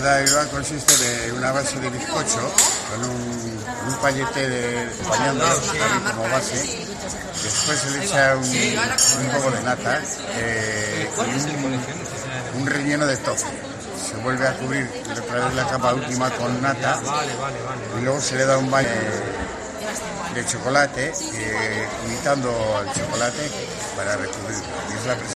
En el Salón Arabe del ayuntamiento